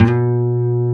C 3 HAMRNYL.wav